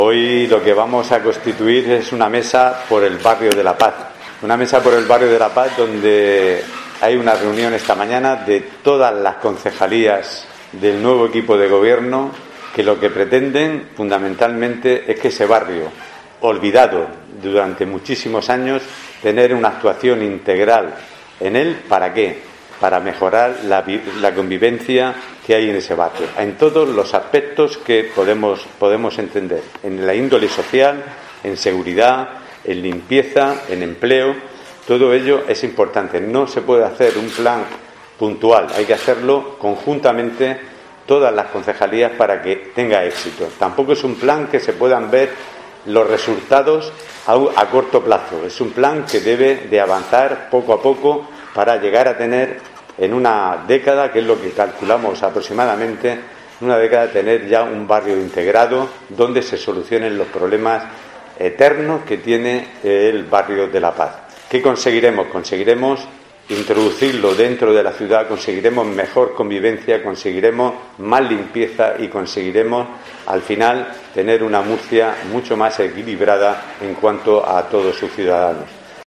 José Antonio Serrano, alcalde de Murcia